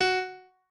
pianoadrib1_16.ogg